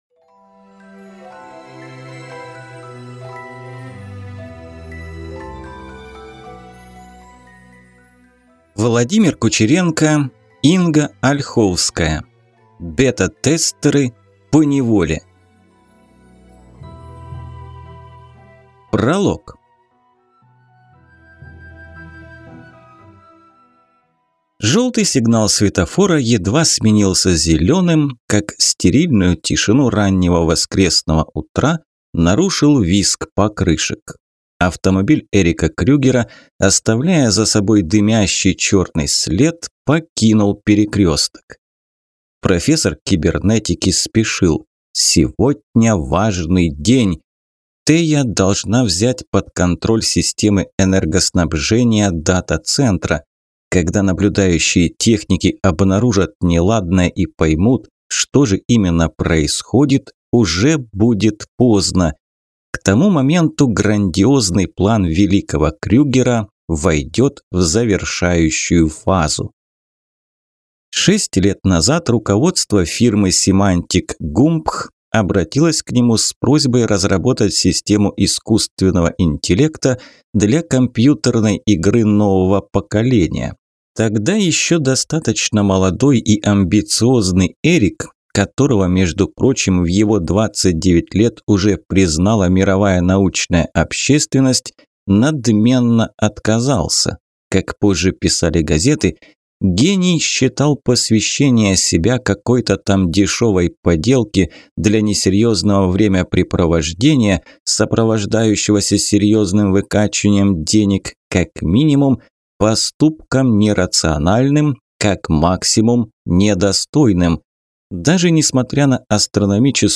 Аудиокнига Бета-тестеры поневоле | Библиотека аудиокниг